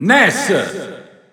Announcer pronouncing Ness's name in Italian.
Ness_Italian_Announcer_SSBU.wav